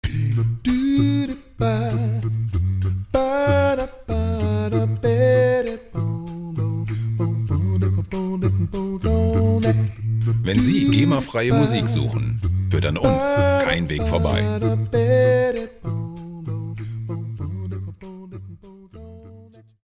Gema-freie a-cappella Musik
Musikstil: a cappella
Tempo: 97 bpm